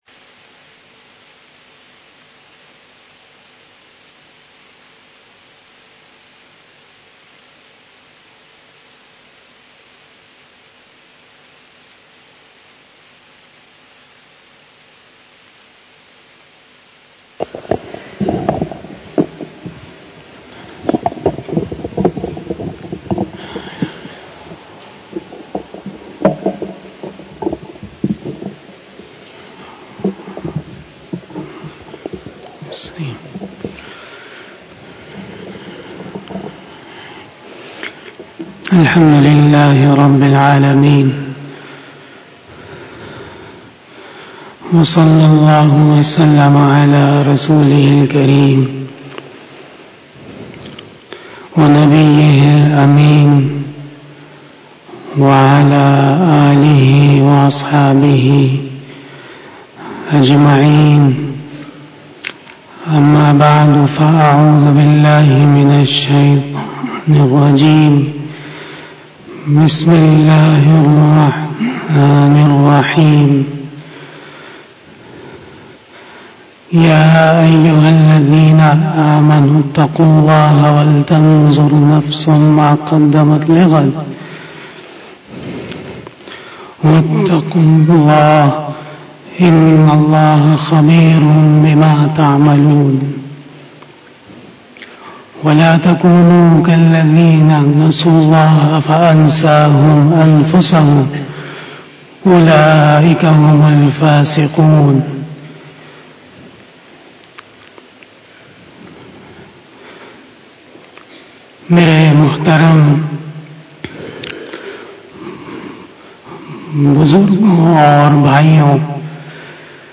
Khitab-e-Jummah
احتیاط، احیاء علوم دین کتاب میں امام غزالی رحمہ اللہ کا قول ***** سوال جواب 36.